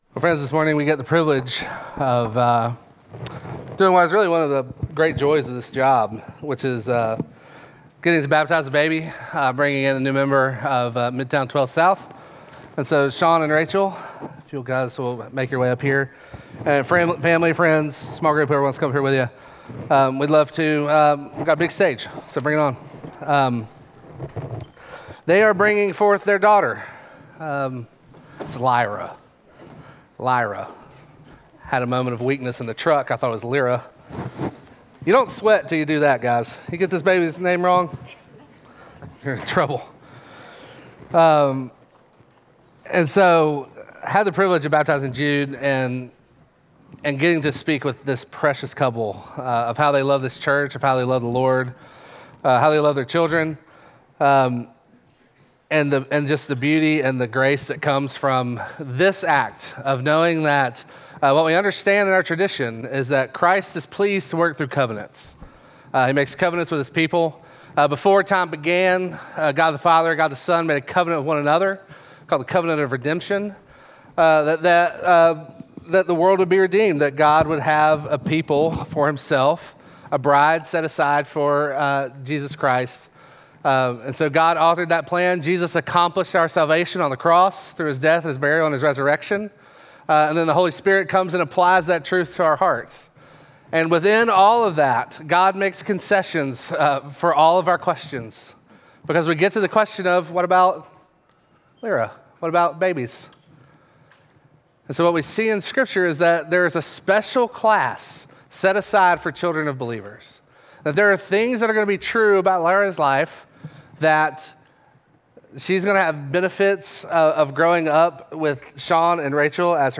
Midtown Fellowship 12 South Sermons Body And Soul Mar 16 2025 | 00:43:48 1x 00:00 / 00:43:48 Subscribe Share Apple Podcasts Spotify Overcast RSS Feed Share Link Embed